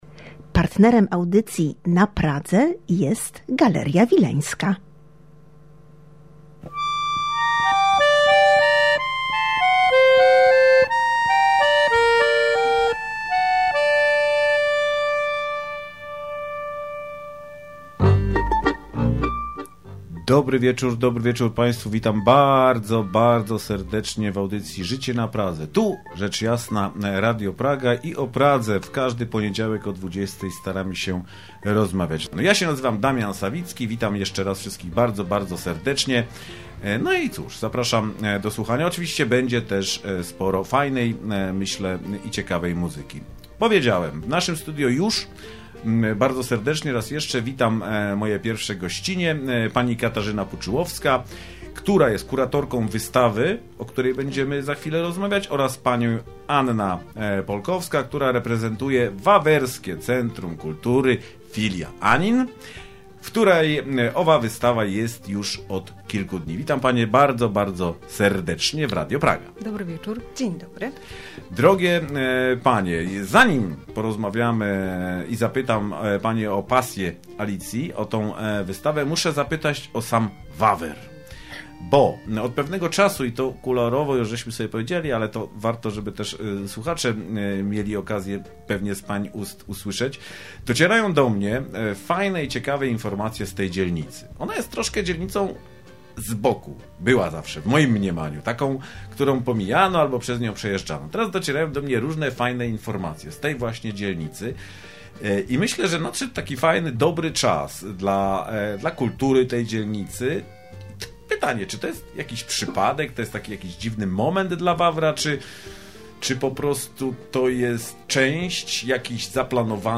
Gościniami audycji ” Życie na Pradze” były Panie